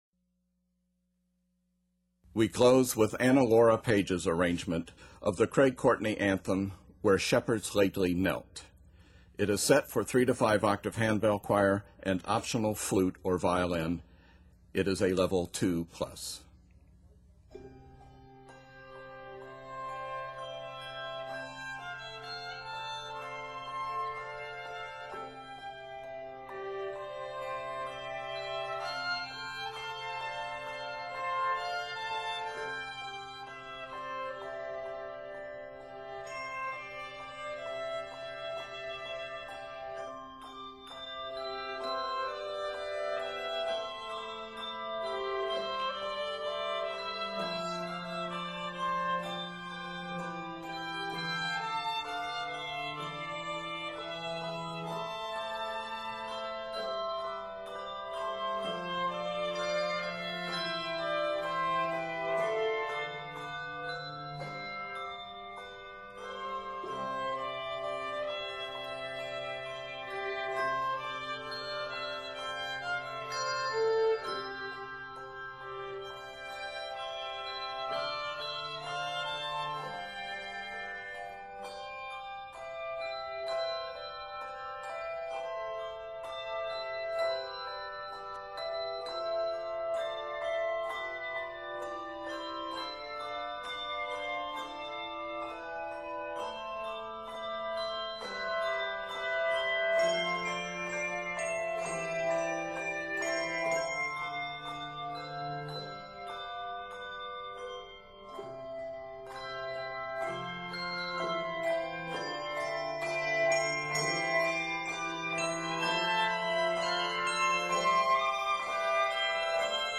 Gentle, flowing textures support the melody
Octaves: 3-5